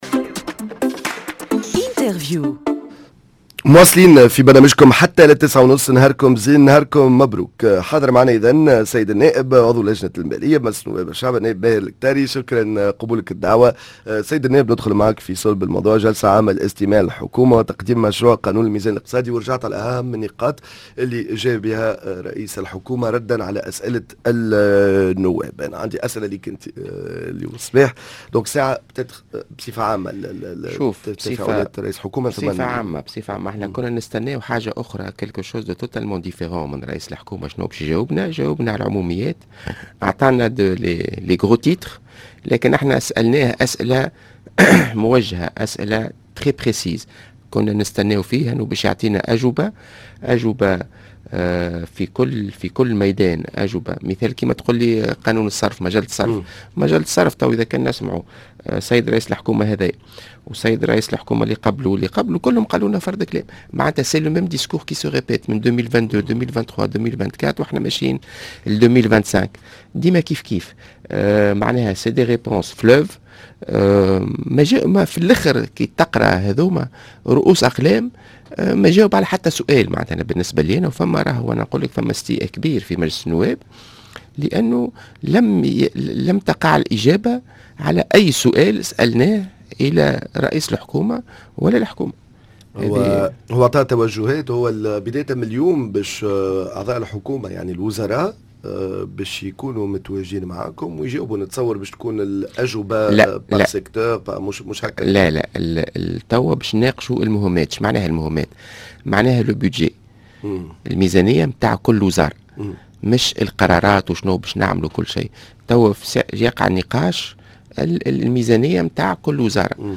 جلسة عامة للإستماع للحكومة و تقديم مشروع قانون الميزان الإقتصادي أكثر تفاصيل مع عضو لجنة المالية بمجلس نواب الشعب النائب ماهر الكتاري